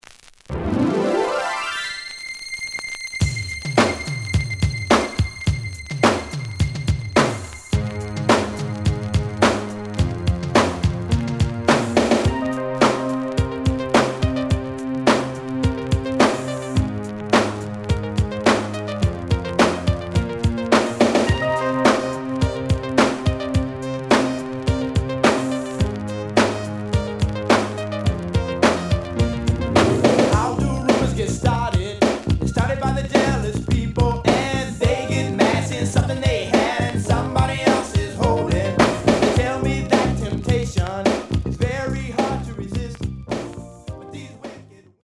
The audio sample is recorded from the actual item.
●Genre: Funk, 80's / 90's Funk
Slight damage on both side labels. Plays good.)